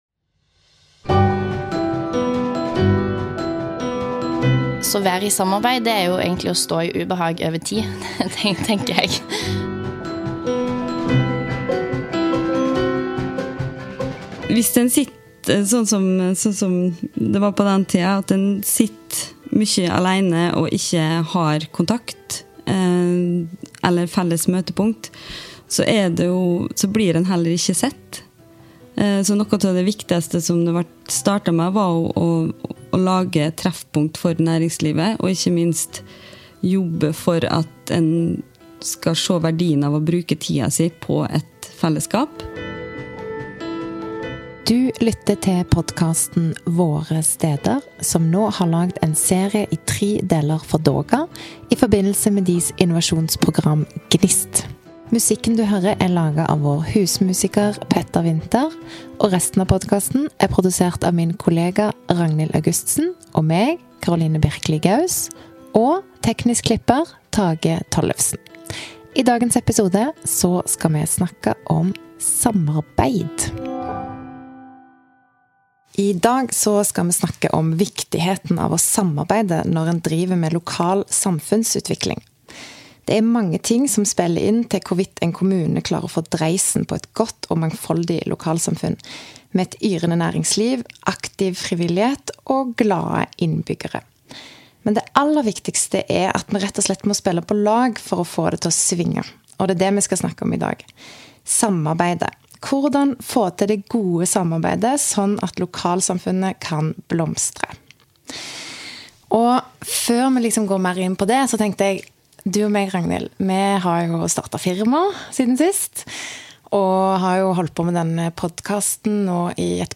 Hvordan få til det gode samarbeidet slik at lokalsamfunnet kan blomstre? For å snakke om verdien av det gode samarbeidet har vi med oss to gjester.